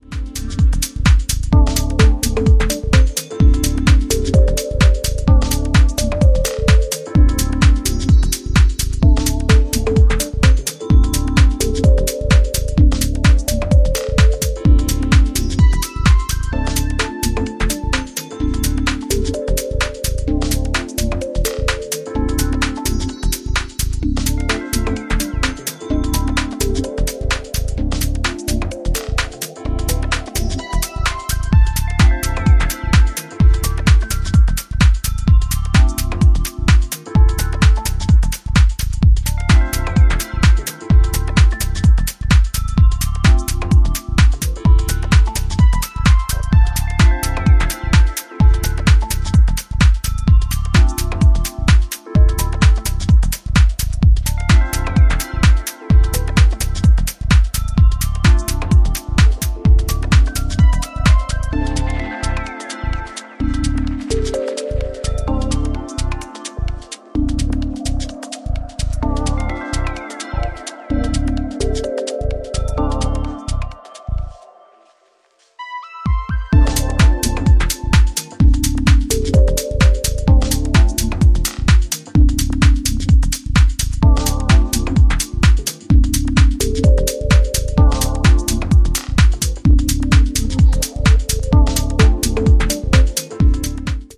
ジャンル(スタイル) MINIMAL / TECH HOUSE / DEEP HOUSE